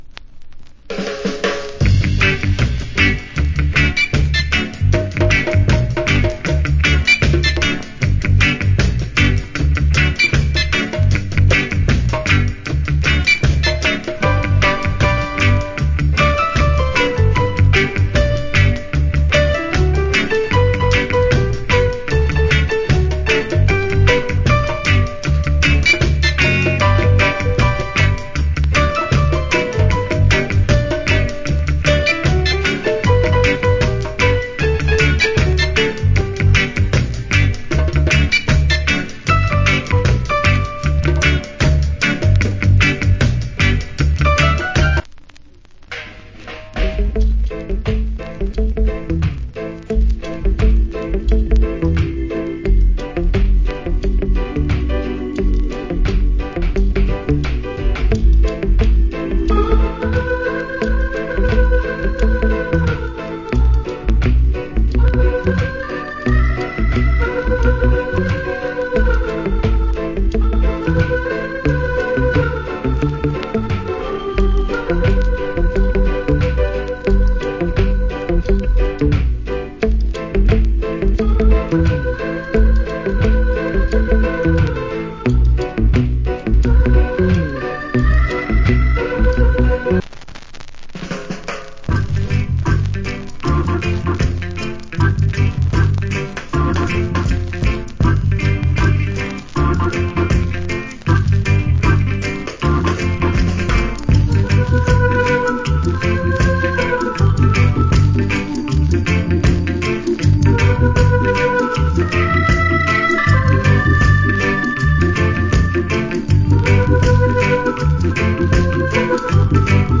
Great Rock Steady Inst.